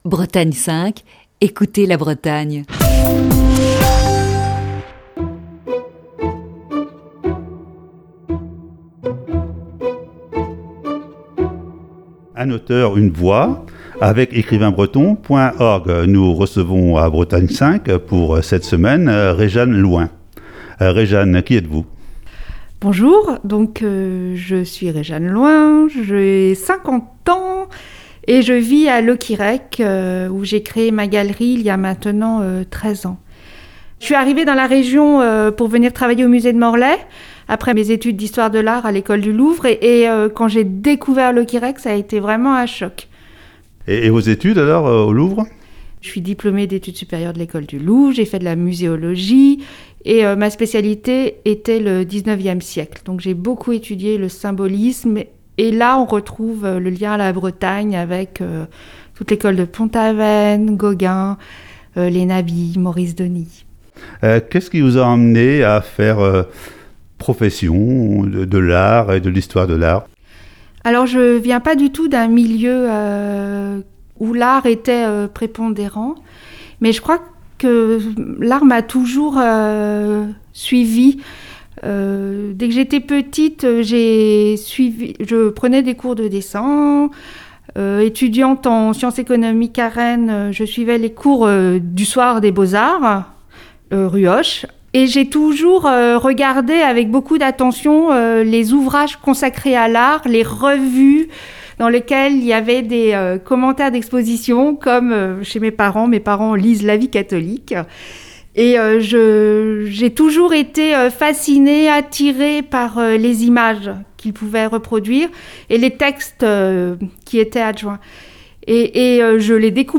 Émission du 3 mai 2021.